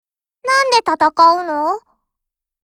Cv-50103_warcry.mp3